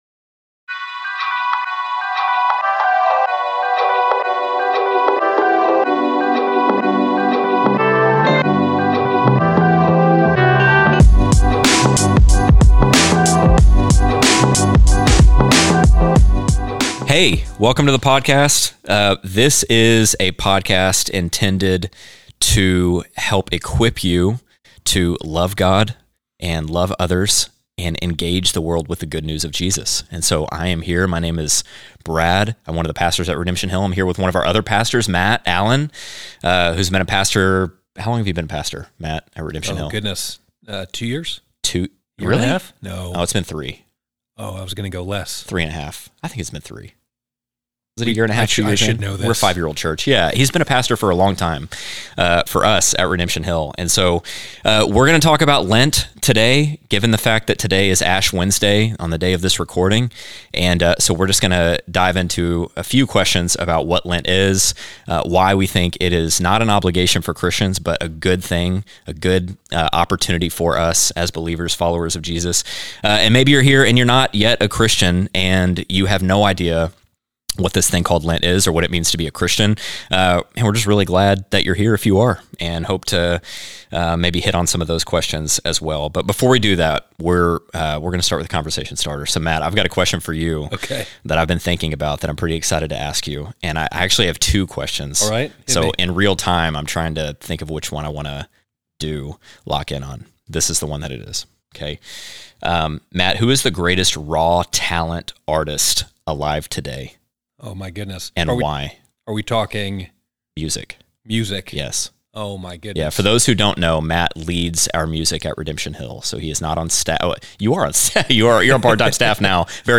Moving past the idea that it is strictly a "Catholic obligation," they explore Lent as a 40-day invitation to return to the Lord. From a debate on the greatest raw-talent musicians to the theological "why" behind fasting, this conversation refocuses the season on communion with God rather than legalistic checklists.